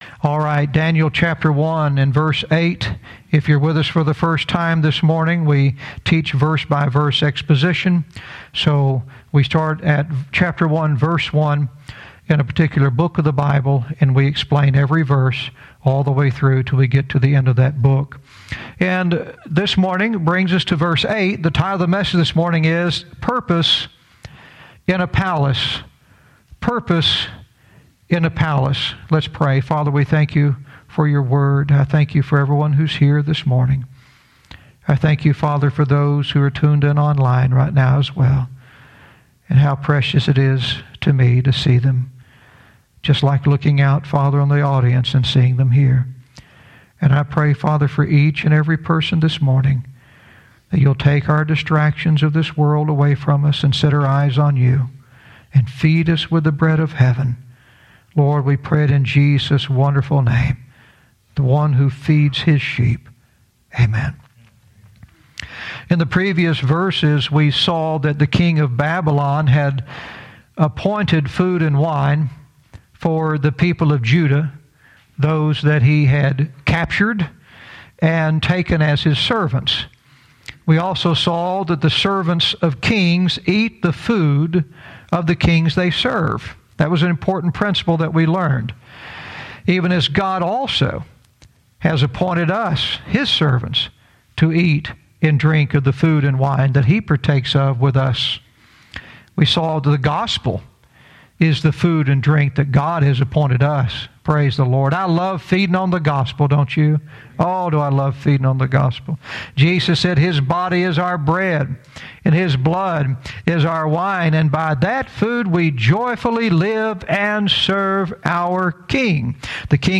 Verse by verse teaching - Daniel 1:8 "Purpose in a Palace"